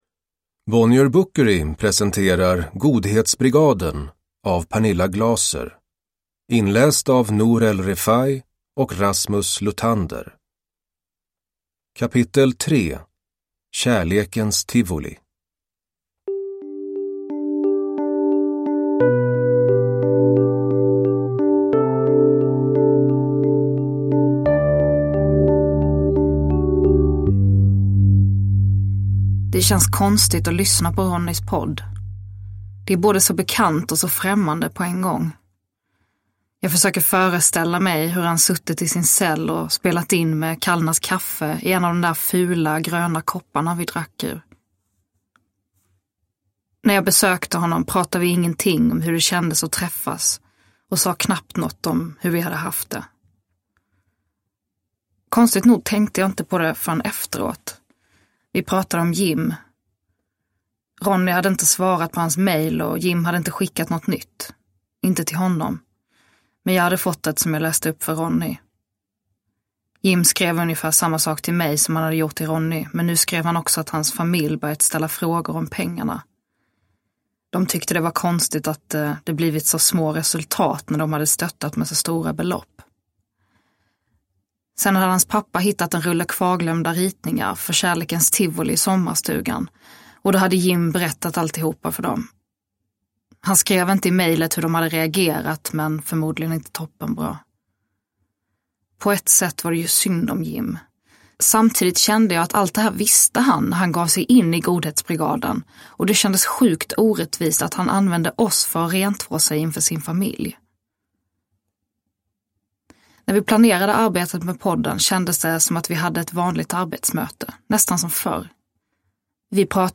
Godhetsbrigaden. S1E3, Kärlekens tivoli – Ljudbok – Laddas ner